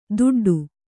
♪ duḍḍu